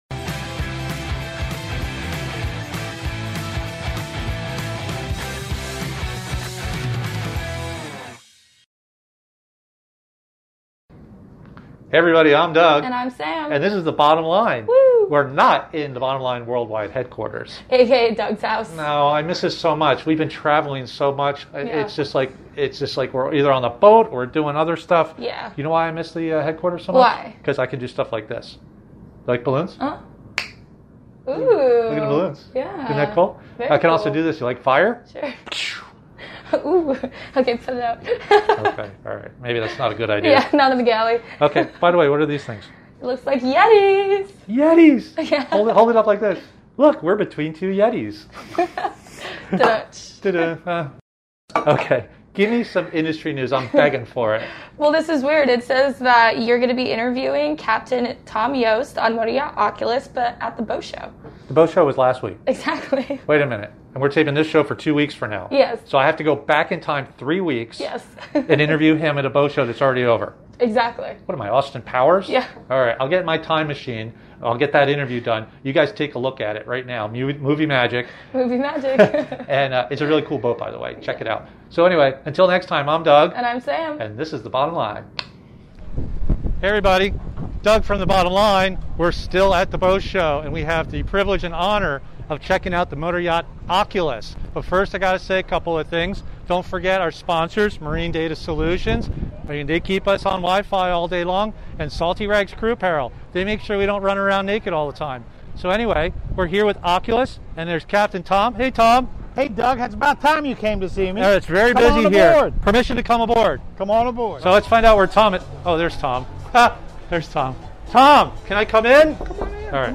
We get a tour of M/Y Oculus and hear a bit about it's history.